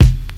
Medicated Kick 15.wav